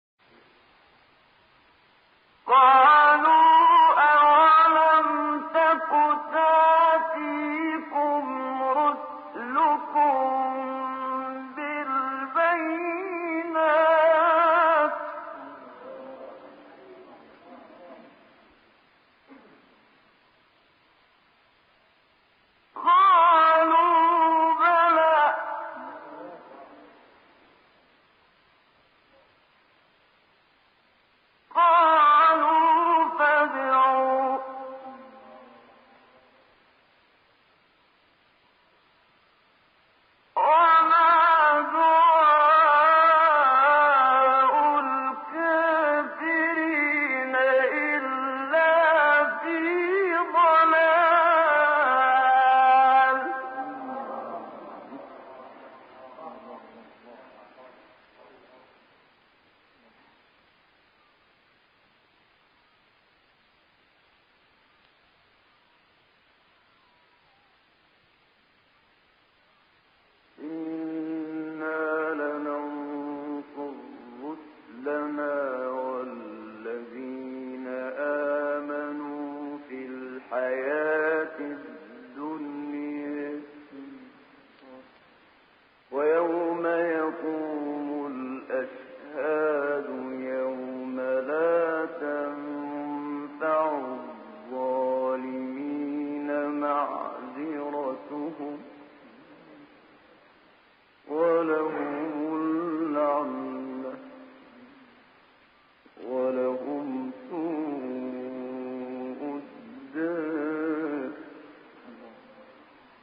منشاوی برای بار دوم آیه را در پرده بم می‌خواند و سپس مجدداً نهاوند را در پرده اوج صدا اجرا می‌کند.
در اینجا منشاوی کشش کلمه «أَقُولُ» را بیش از حد می‌کشد تا نشان دهد که آنچه دعوت‌کننده بیان کرده مطالب پرشمار و بااهمیتی بوده است.